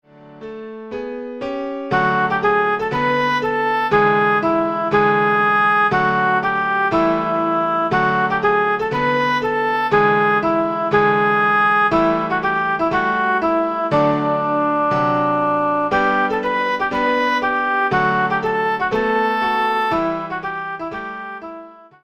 Sample from the Backing MP3